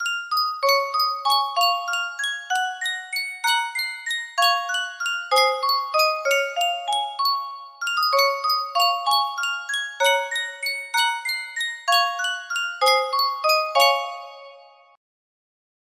Reuge Music Box - BMIATEYC 7005 music box melody
Full range 60